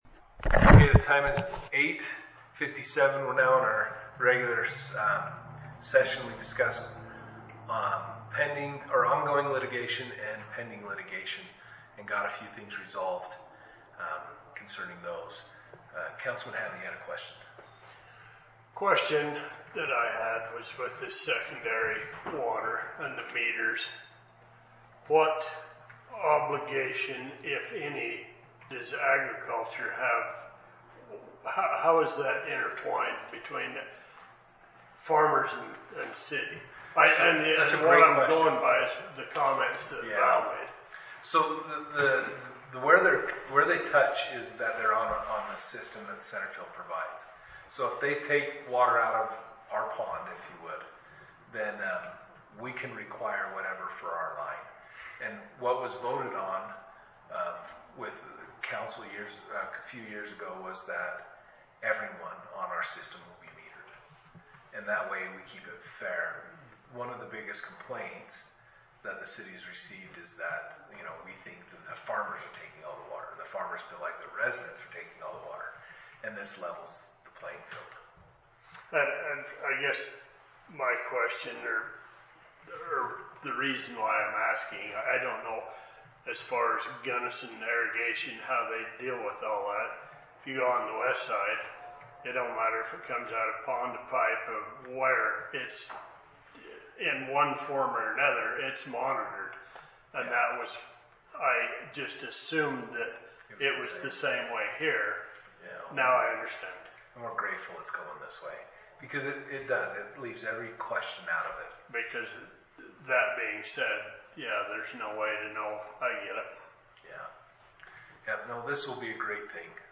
Meeting
The meeting will be held in the Centerfield City Hall, 130 South Main, Centerfield; which meeting will begin promptly at 7:00 p.m. The agenda shall be as follows